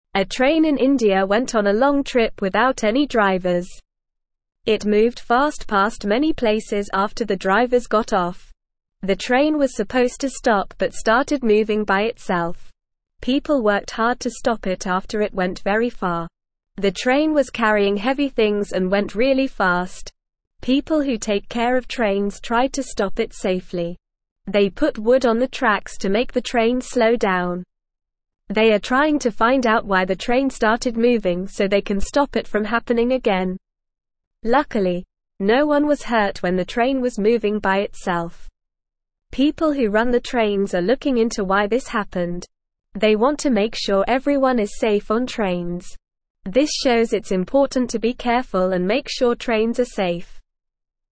Normal
English-Newsroom-Lower-Intermediate-NORMAL-Reading-Runaway-Train-in-India-Goes-on-Long-Trip.mp3